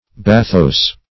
Bathos \Ba"thos\ (b[=a]"th[o^]s), n. [Gr. ba`qos depth, fr.